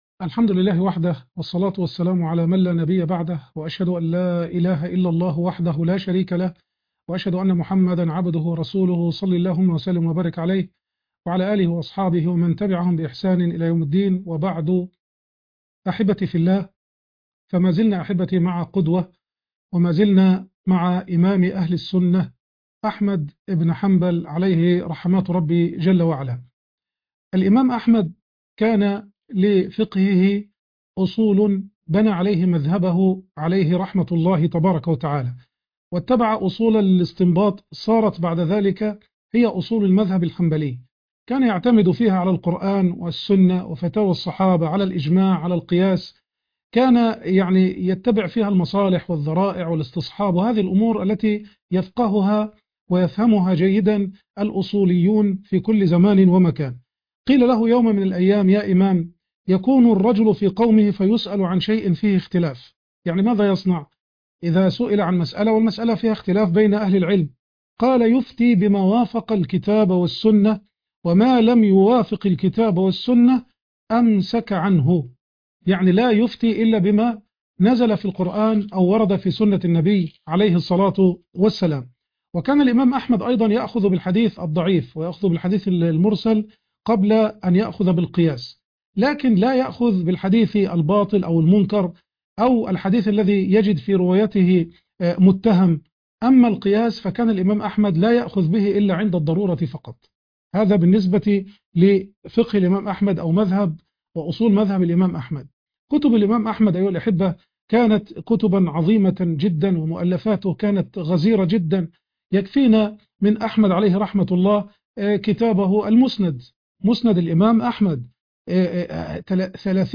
المقرأة - سورة هود ص 231